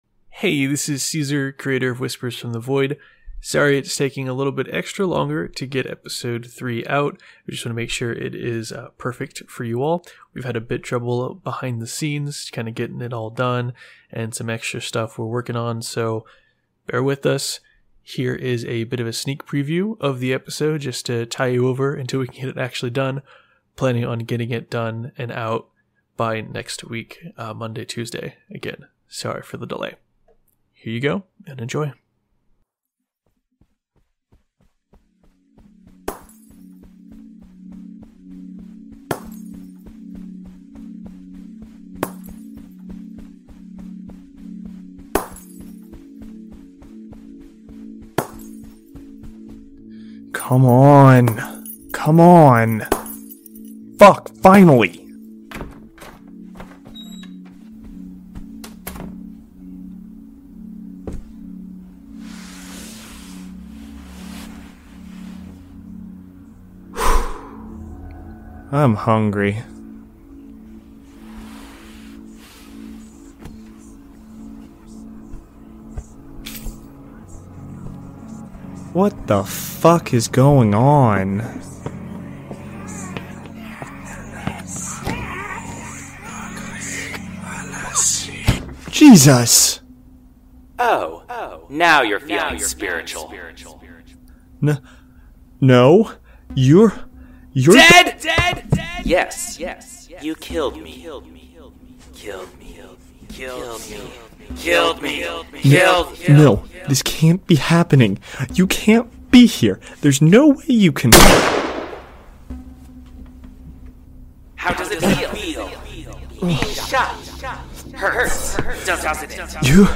Play Rate Apps Listened List Bookmark Share Get this podcast via API From The Podcast Whispers From the Void Whispers from the Void is an immersive audio drama that plunges listeners into a world where the boundaries between reality and the supernatural are blurred. Follow the gripping tale of Richard Harrison and Jason Vasquez as they unravel the dark secrets hidden within their city, confronting eldritch horrors and ancient cults that threaten their sanity and existence.